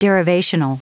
Prononciation du mot derivational en anglais (fichier audio)